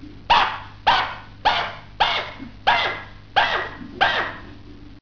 Вопль шиншиллы